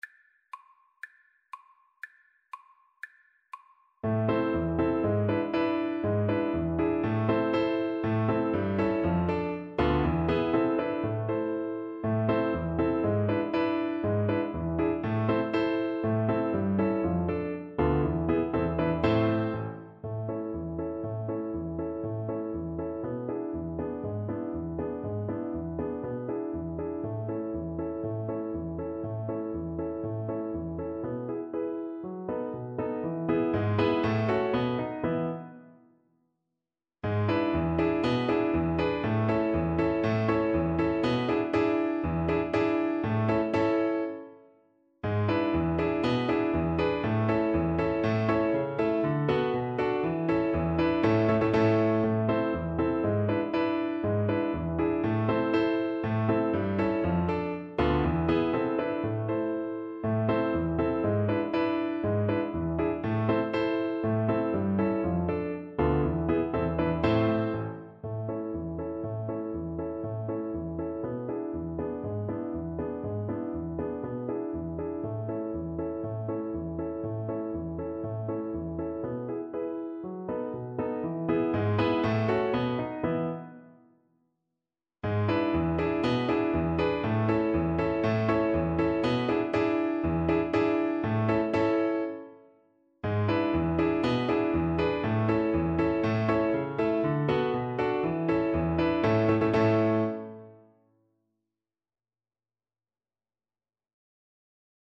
2/4 (View more 2/4 Music)
Classical (View more Classical Violin Music)